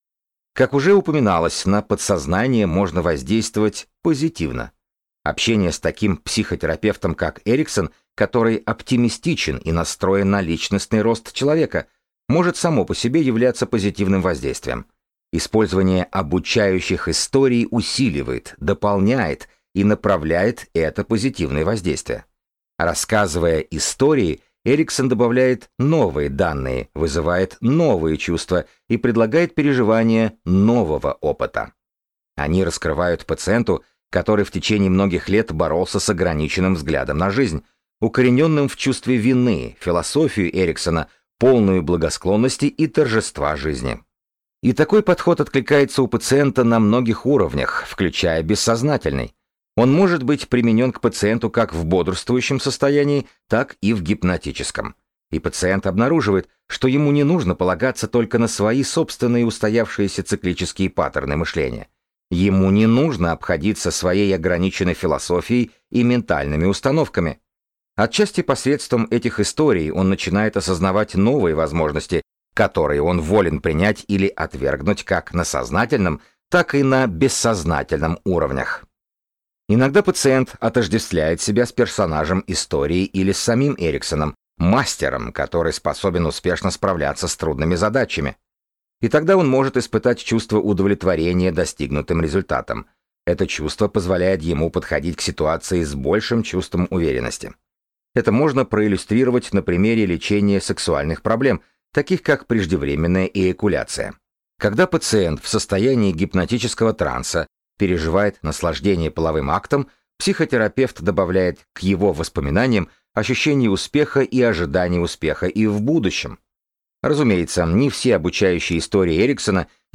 Аудиокнига Изменяя бессознательное | Библиотека аудиокниг